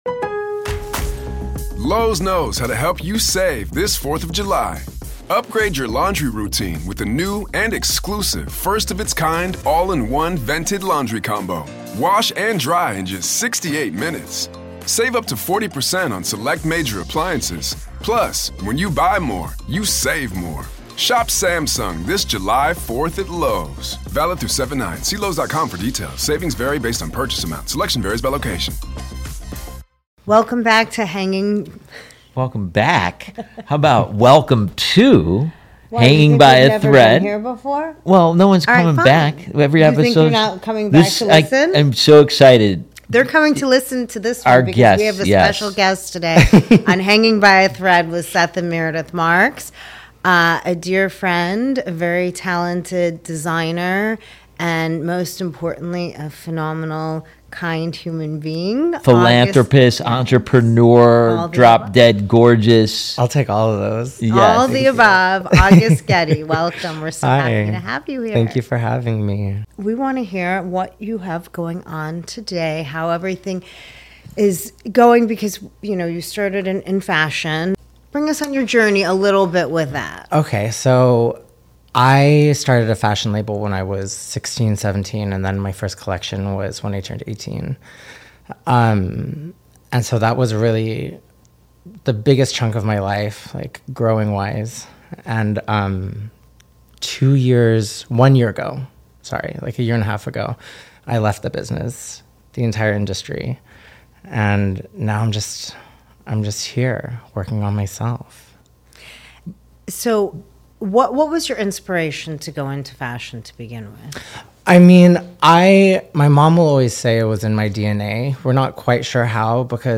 We discuss the impact of his journey to sobriety, shedding light on how personal growth has intricately woven into both his work and connections with others. Join us for a personal conversation with August Getty as we navigate the threads of his life, exploring the fusion of art, identity, recovery, and the unique spirit that shapes his artistic vision and personal relationships.